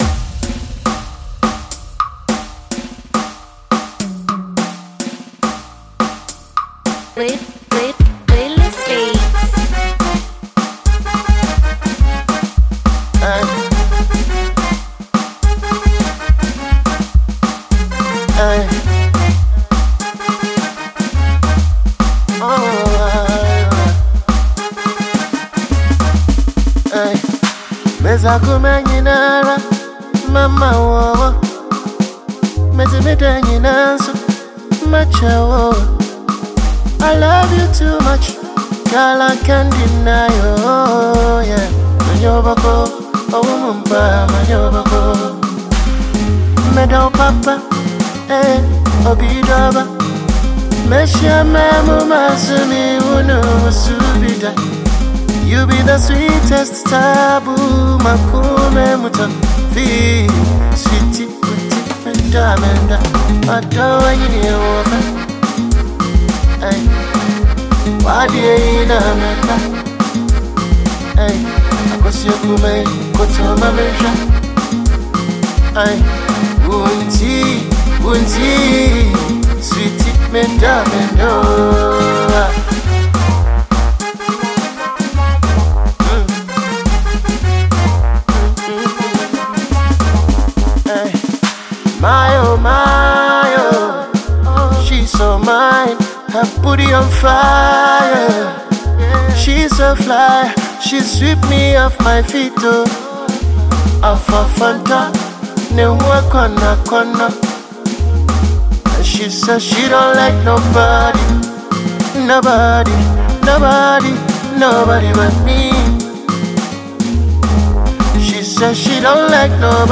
Ghana Music
highlife